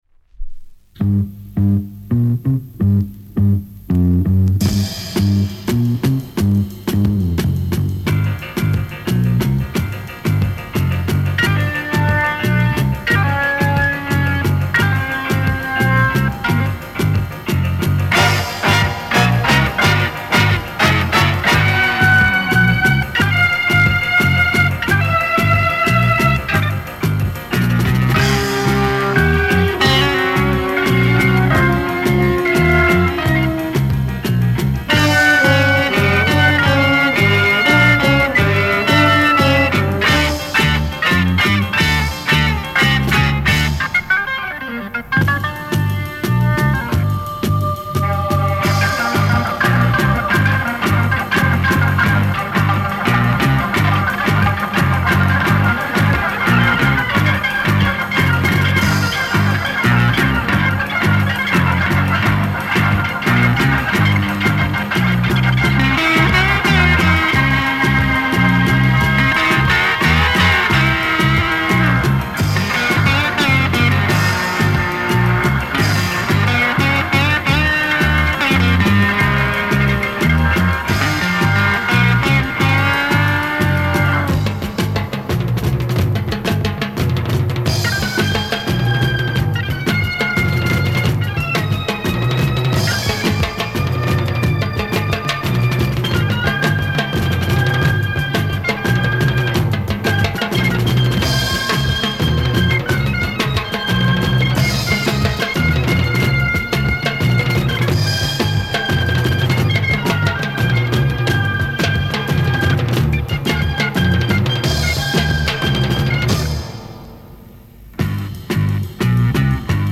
Portuguese Freakbeat Mod 2Siders